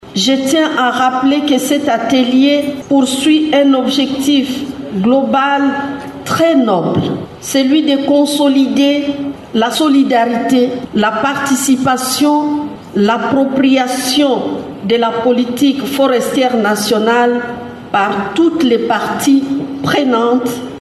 Un extrait de l’allocution de la ministre de l’Environnement :